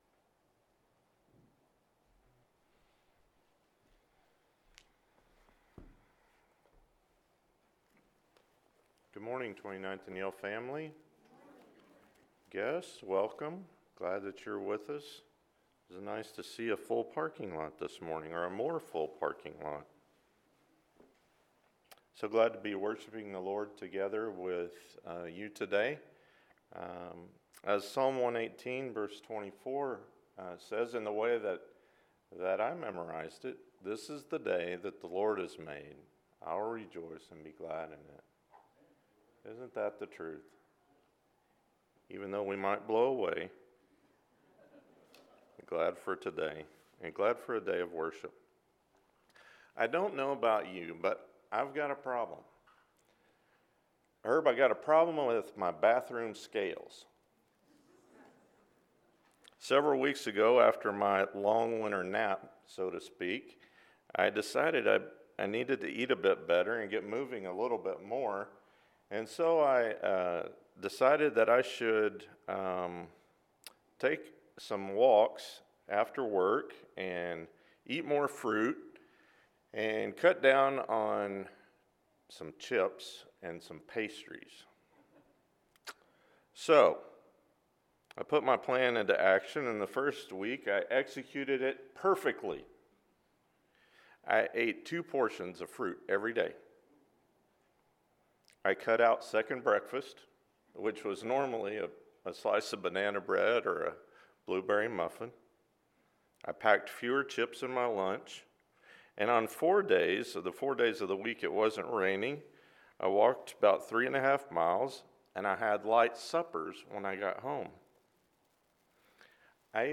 On the Road Together with Jesus and His Disciples – Sermon